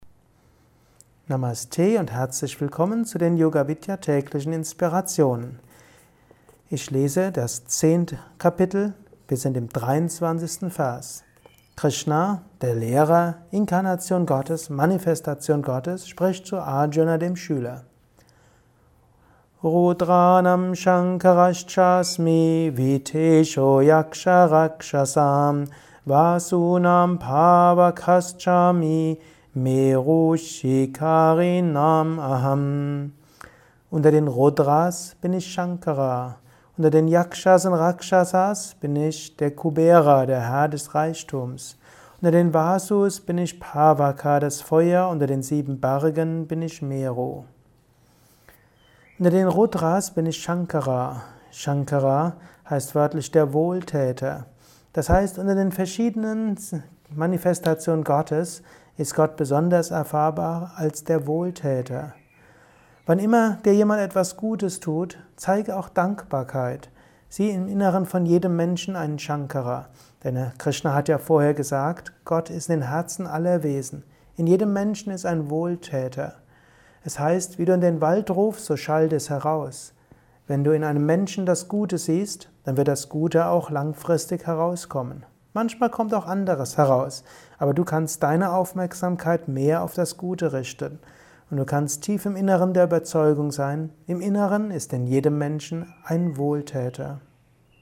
Höre einen kurzen Beitrag zur Bhagavad Gita Kapitel X. Vers 23: Gott ist leicht erfahrbar in der Wohltätigkeit. Siehe im Menschen das Gute. Dies ist ein kurzer Kommentar als Inspiration für den heutigen Tag